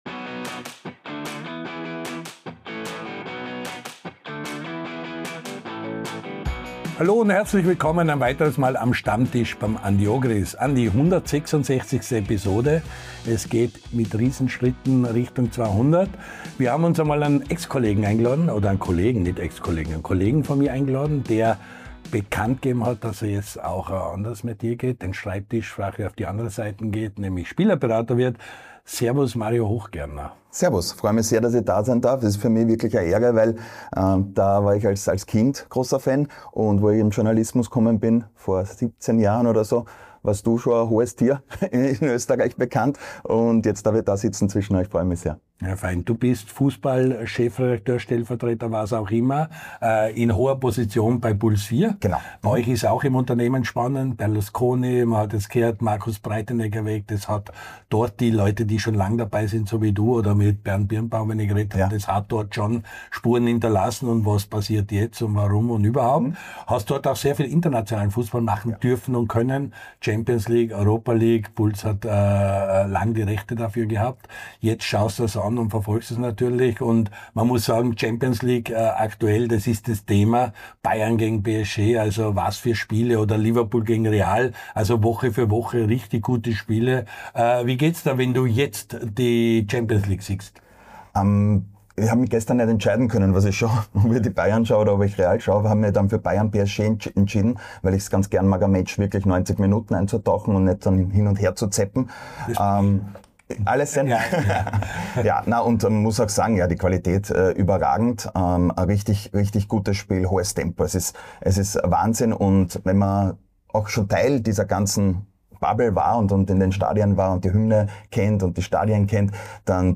Der LAOLA1-Kult-Talk von und mit Andy Ogris!